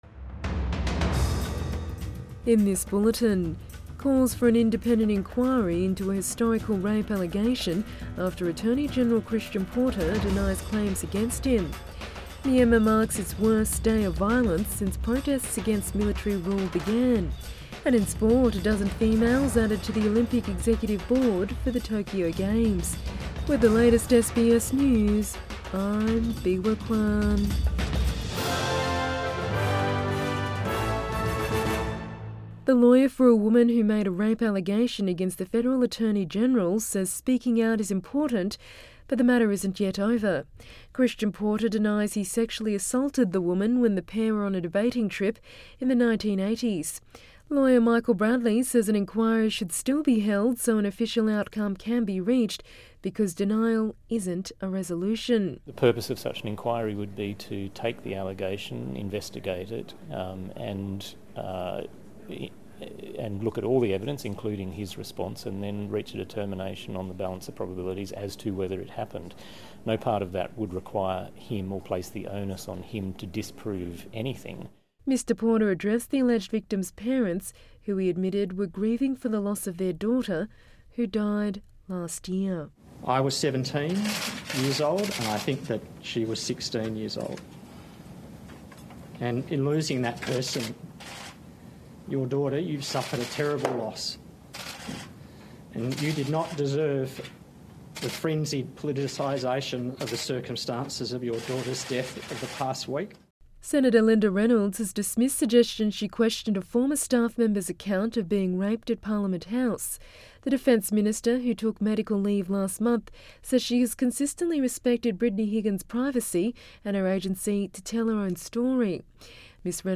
AM bulletin 4 March 2021